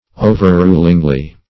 -- O`ver*rul"ing*ly , adv.
overrulingly.mp3